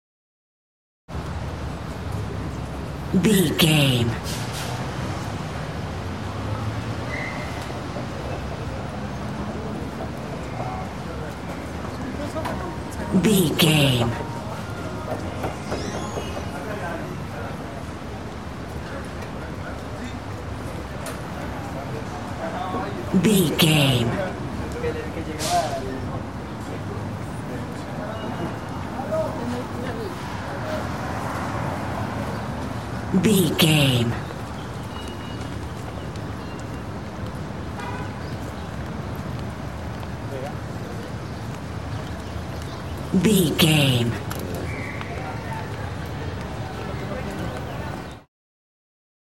City street low traffic pedestrian wallas
Sound Effects
urban
ambience